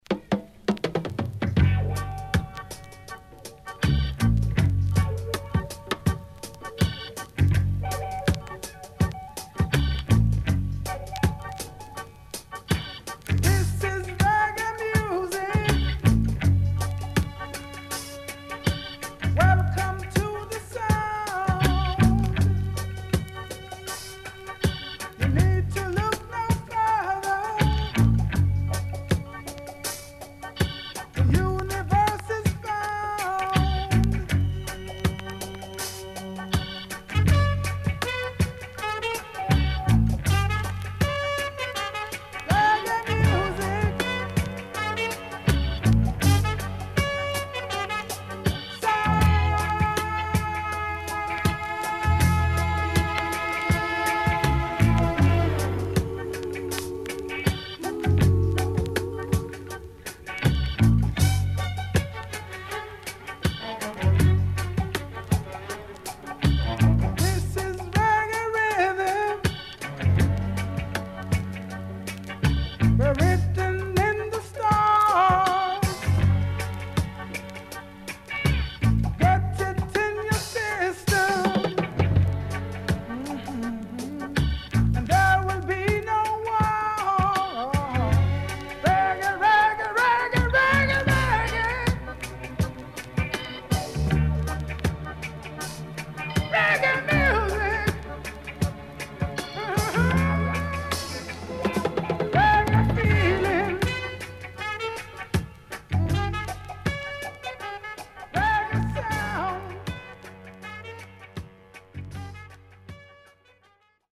SIDE B:少しチリノイズ、プチノイズ入ります。